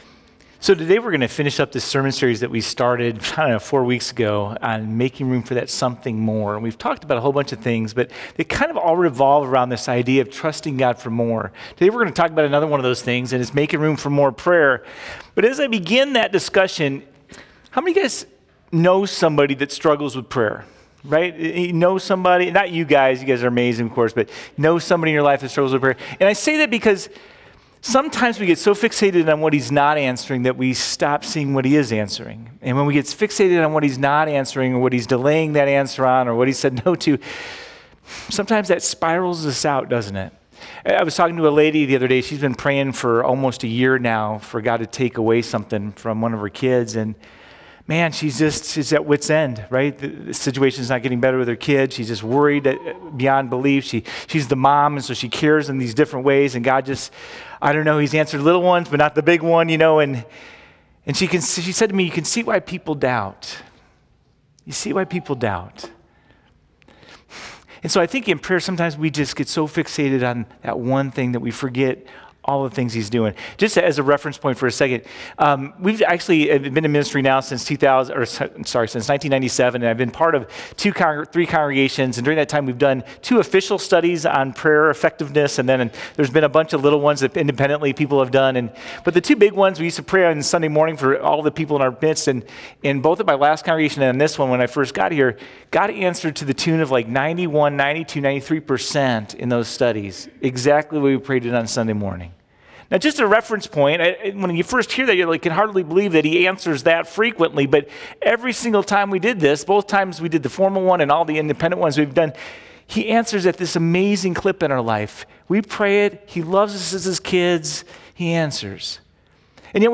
0428-Sermon.mp3